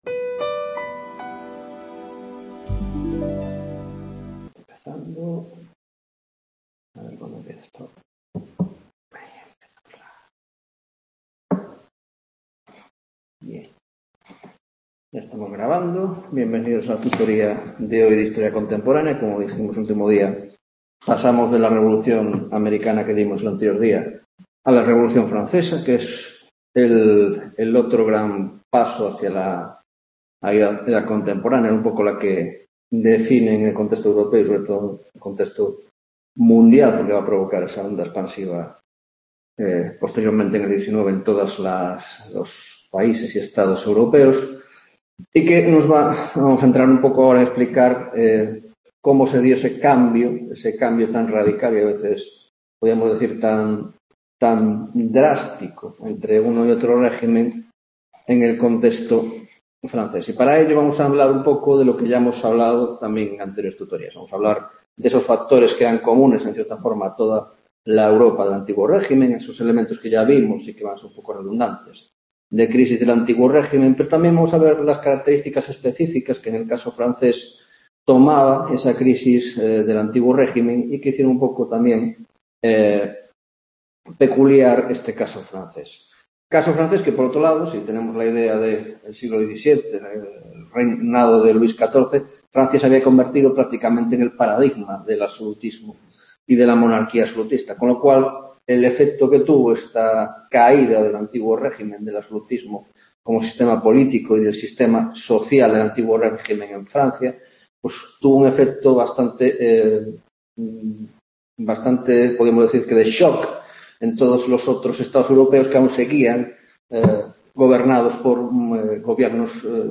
3ª Tutoría de Historia Contemporánea - Revolución Francesa, 1ª parte